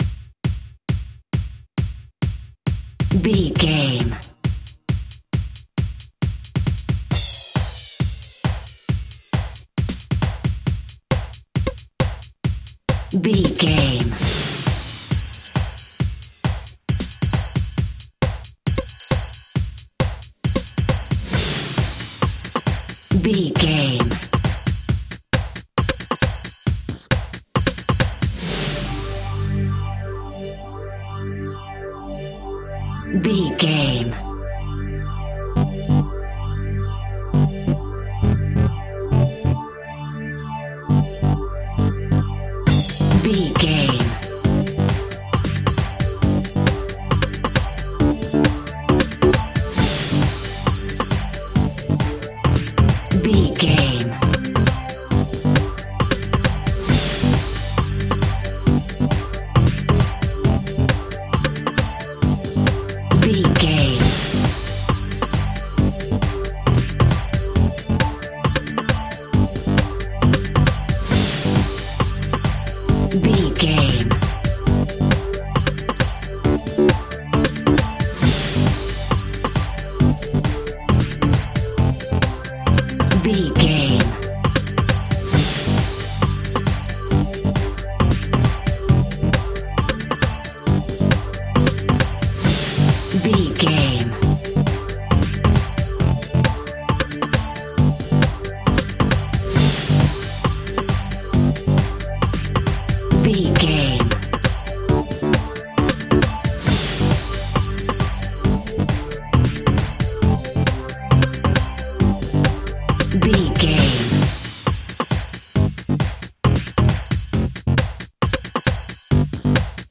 Epic / Action
Fast paced
Ionian/Major
Fast
aggressive
powerful
funky
groovy
driving
energetic
synthesiser
drums
drum machine
electro house
house music